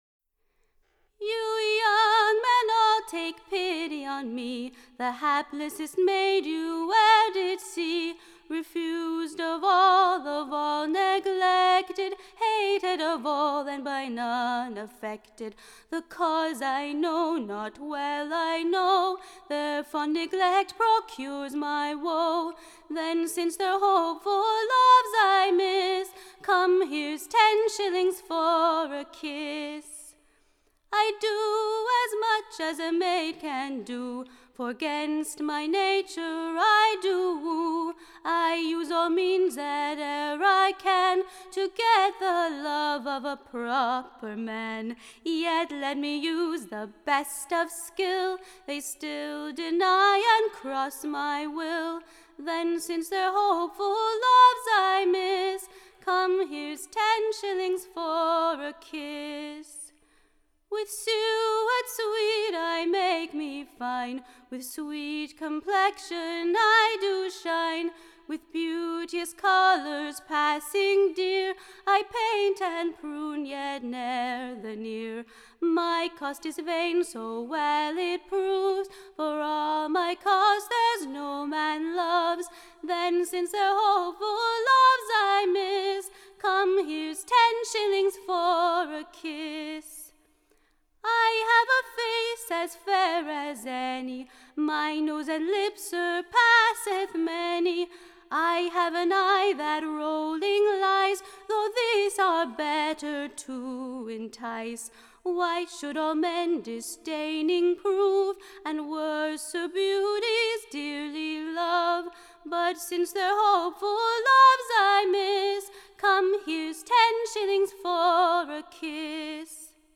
Recording Information Ballad Title This Maide vvould giue tenne / Shillings for a Kisse: Tune Imprint To the Tnne of Shall I wrastle in despaire.